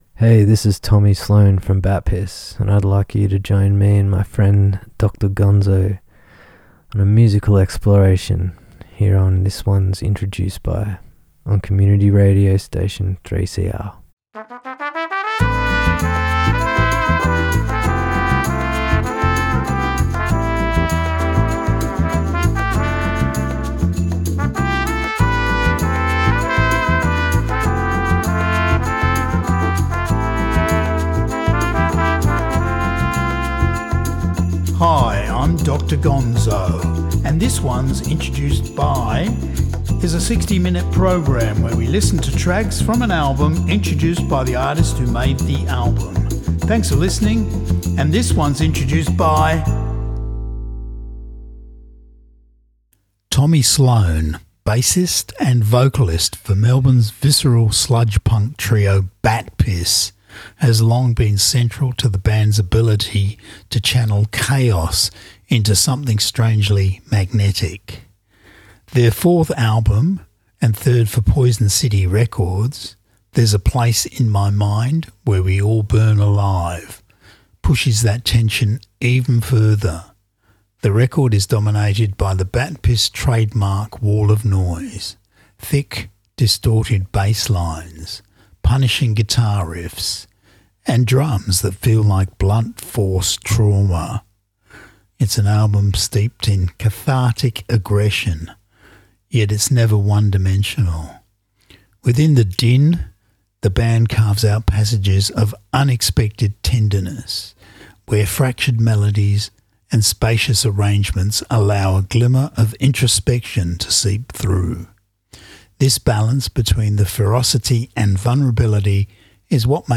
bass and vocals
visceral sludge-punk trio